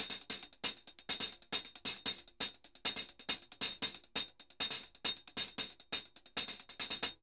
HH_Merengue 136-2.wav